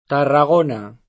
vai all'elenco alfabetico delle voci ingrandisci il carattere 100% rimpicciolisci il carattere stampa invia tramite posta elettronica codividi su Facebook Tarragona [ tarra g1 na ; sp. tarra G1 na ; cat. t 9 rr 9G1 n 9 ] top. (Sp.)